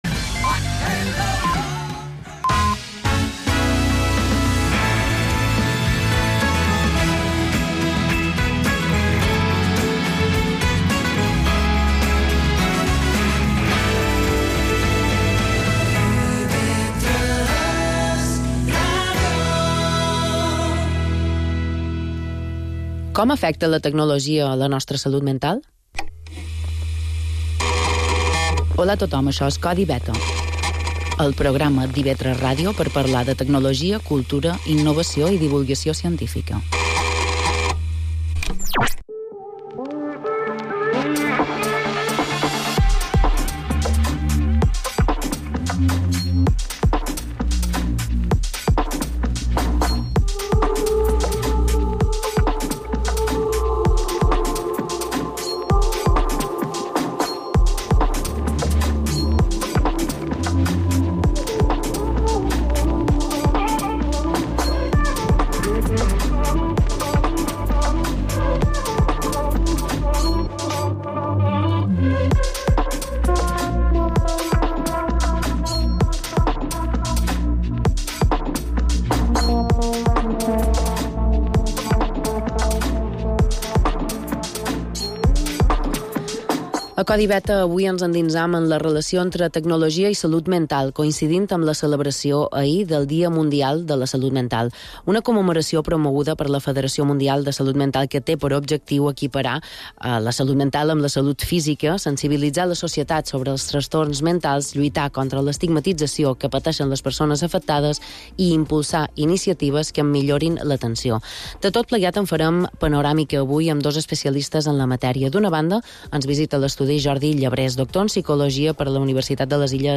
-Emissió a IB3 Ràdio: dissabtes de 23 a 00h.